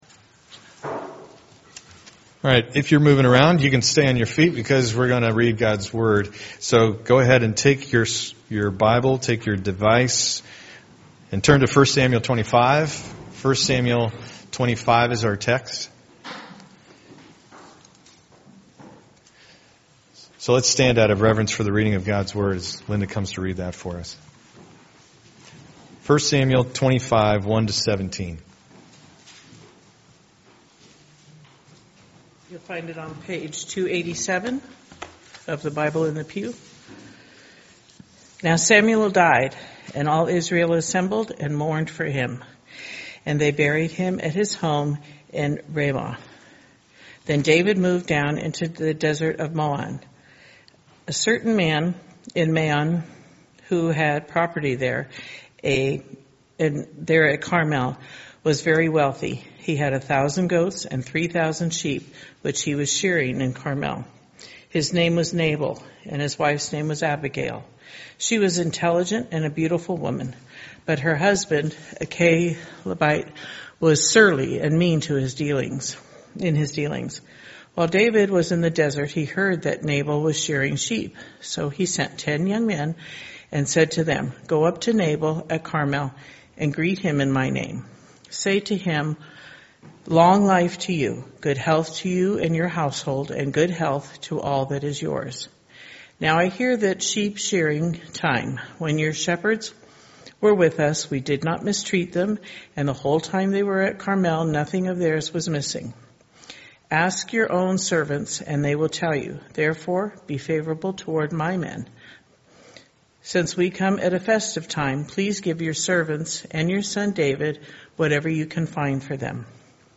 Sermons – 2019 | New Life Church, SF | Becoming.